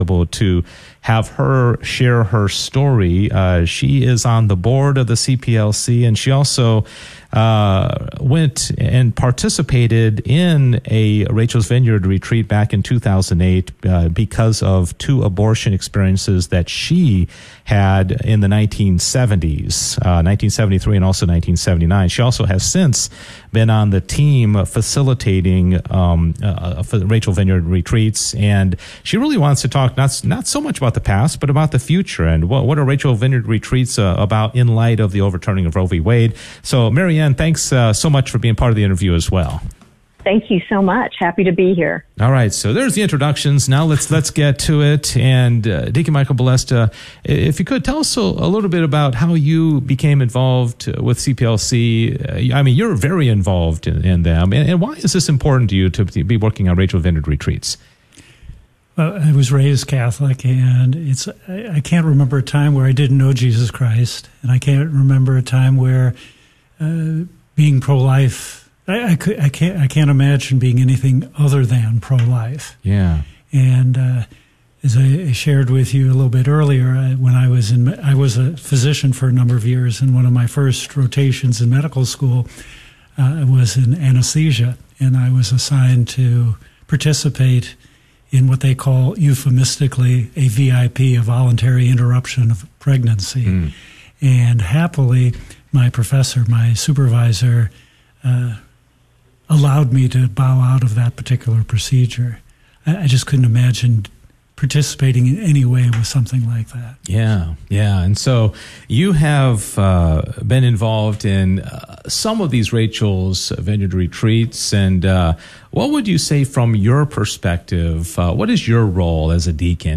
KATH Interview of the Week
KATH Interview of the Week KATH Interview of the Week - Saturday October 14, 2023 Recorded on Saturday October 14, 2023 Share this episode on: KATH Interview of the Week The KATH Interviews of the Week air each Saturday during the 3pm hour on KATH 910 AM on the Guadalupe Radio Network. The interviews cover an eclectic blend of topics with the common bond that they all cover topics that are both Catholic and local in nature. The purpose of these interviews is to inform and educate North Texas listeners about Catholic news and events that are happening in the Dallas and Fort Worth diocese.